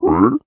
Minecraft Version Minecraft Version 25w18a Latest Release | Latest Snapshot 25w18a / assets / minecraft / sounds / mob / piglin / jealous3.ogg Compare With Compare With Latest Release | Latest Snapshot
jealous3.ogg